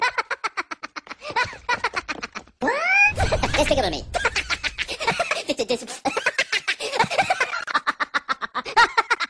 Minion Laughing